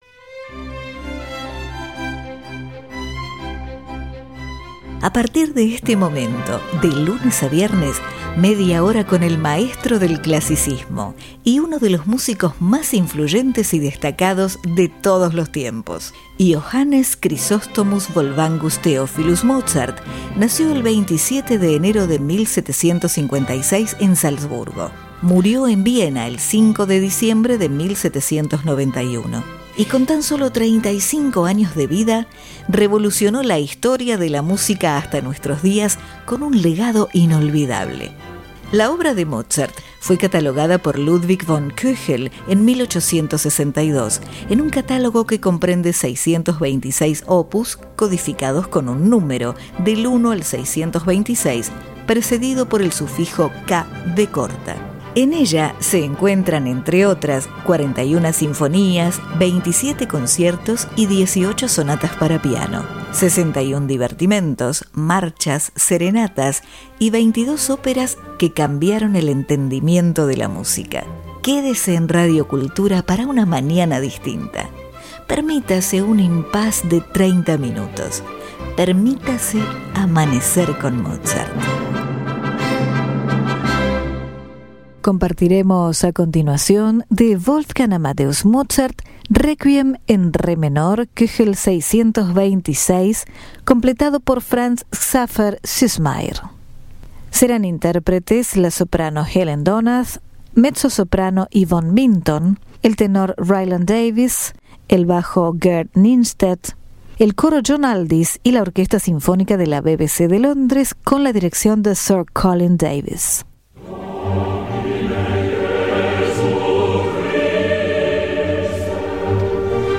Requiem En Re Menor
Orquesta Sinfonica De La BBC De Londres
Coro John Alldis